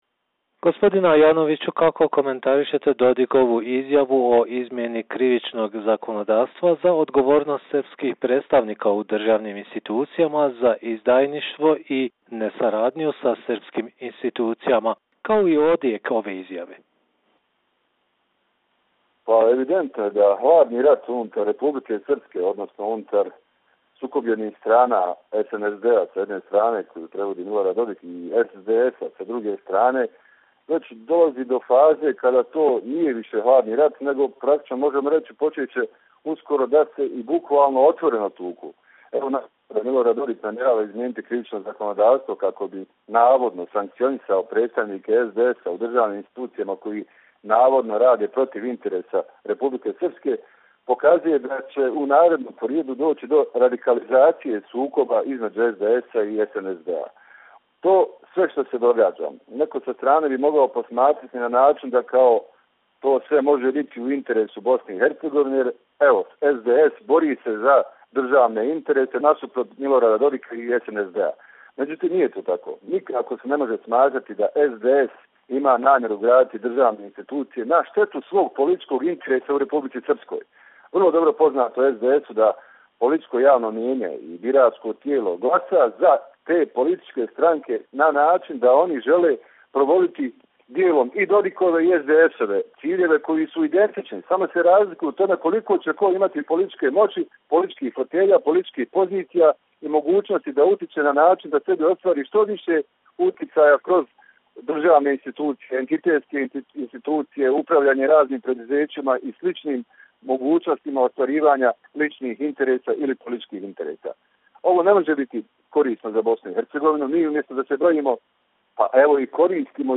Tonske izjave